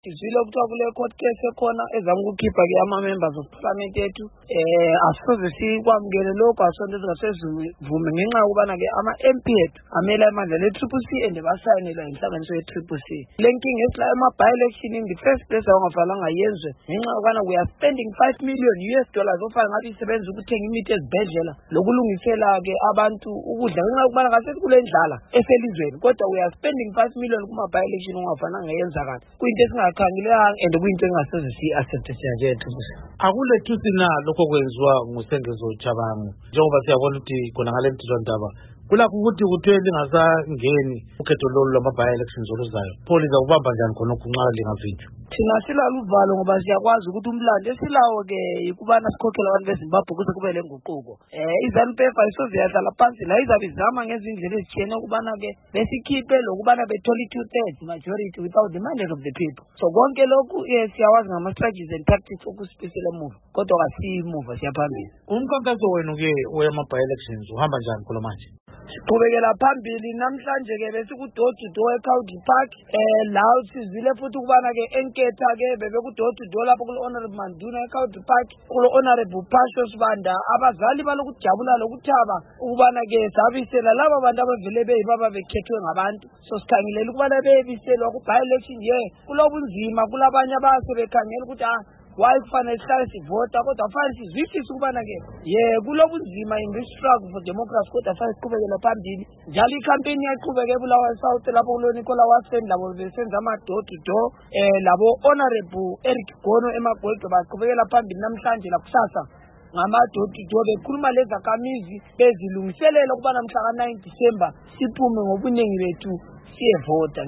Igxoxo Esiyenze LoMnu. Gift Ostallos Siziva